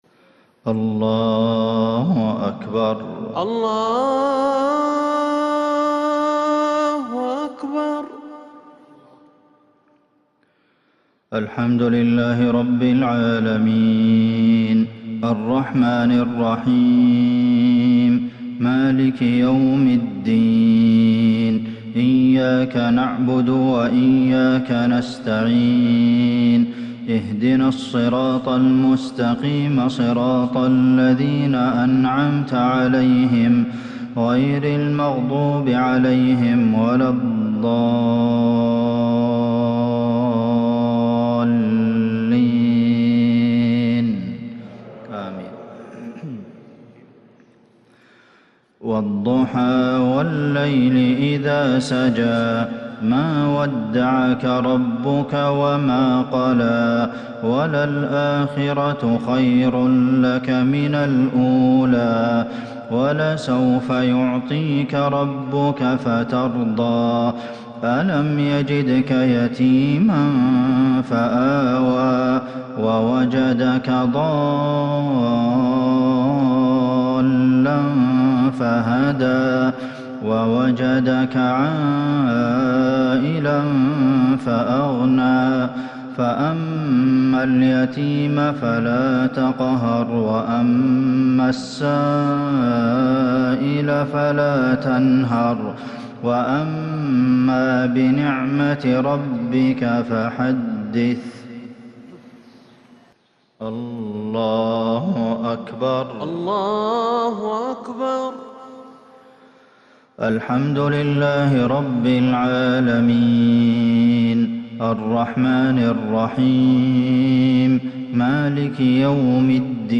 مغرب الجمعة 2-6-1442هـ سورتي الضحى والتكاثر | Maghrib prayer Surah adh-Duha and At-Takathur 15/1/2021 > 1442 🕌 > الفروض - تلاوات الحرمين